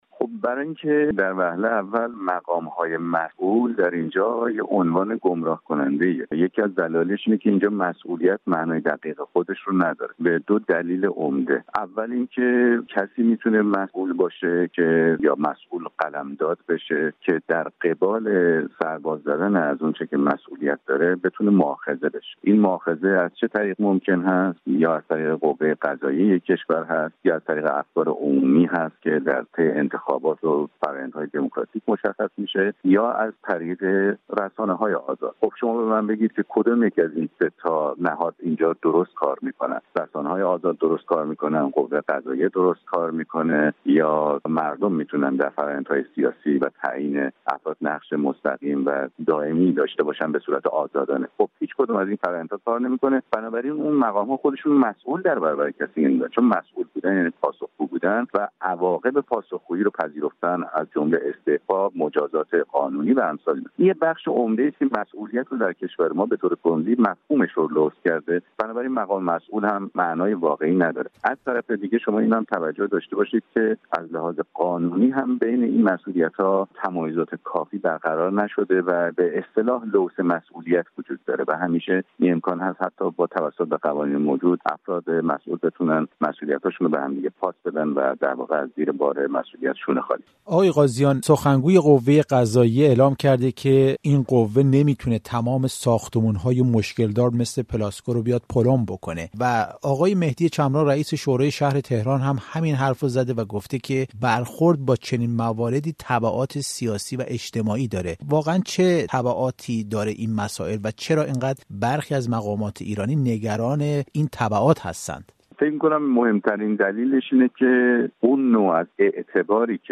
در گفت‌وگو با رادیو فردا